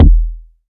KICK.45.NEPT.wav